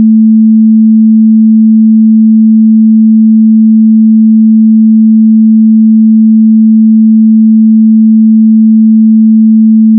sin220s.wav